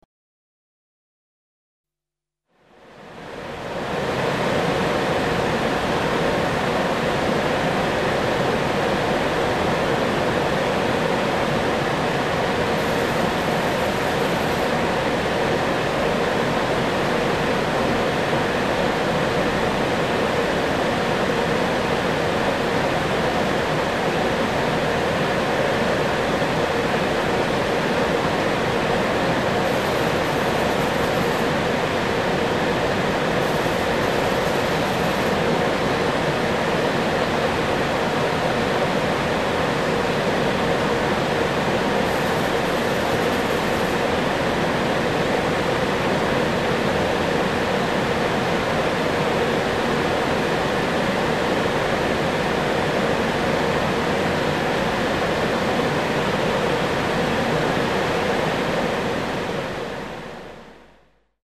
Компьютерная комната
kompyuternaya_komnata_km2.mp3